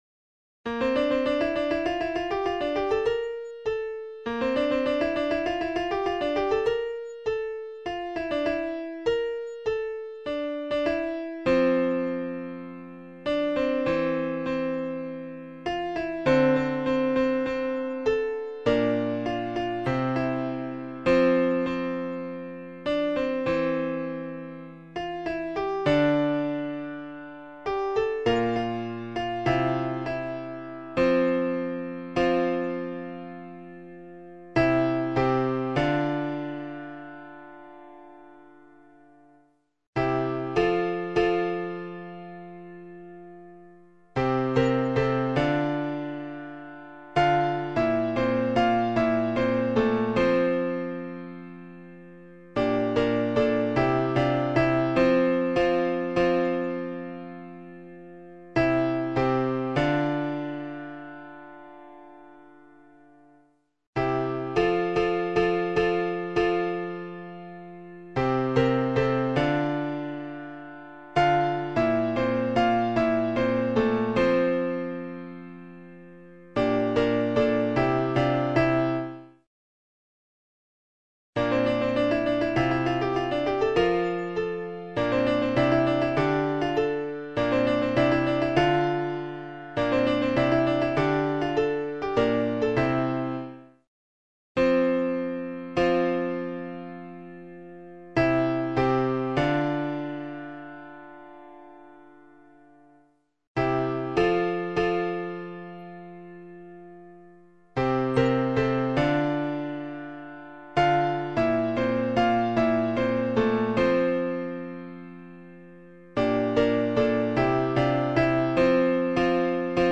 Information and practice vocal scores & mp3's for the